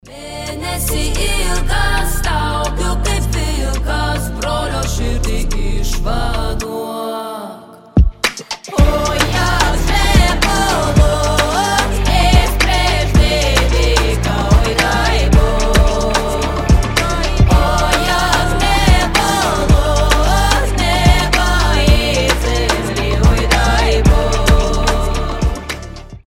• Качество: 320, Stereo
фолк